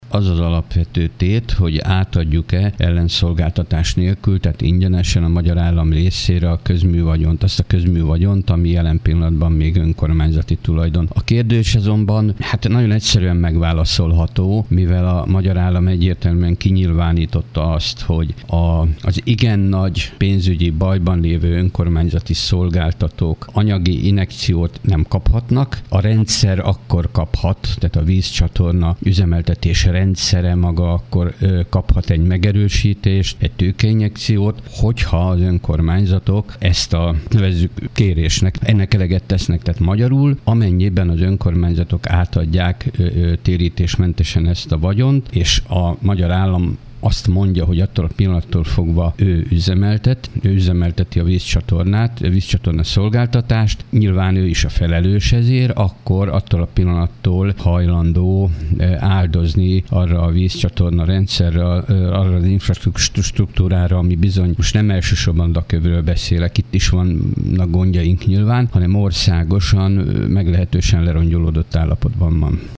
Fazekas László, Albertirsa polgármestere vázolta fel, miről szól az intézkedés.